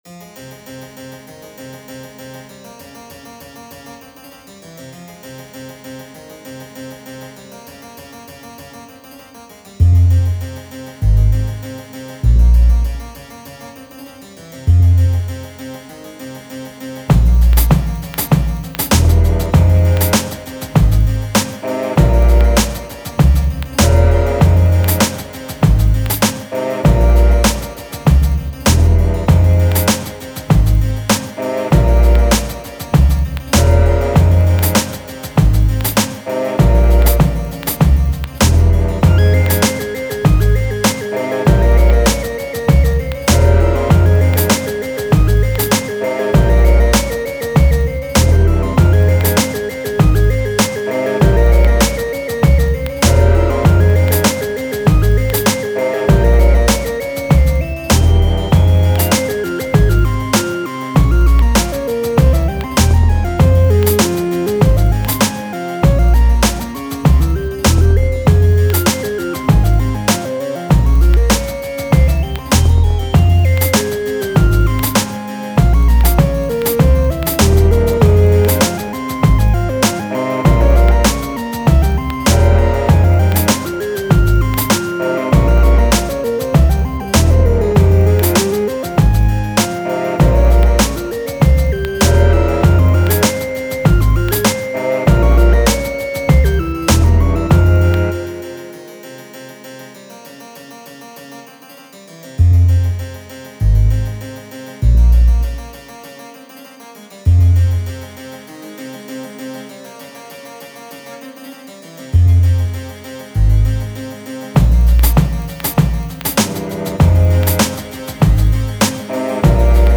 Haunting harpsichord hip hop groove.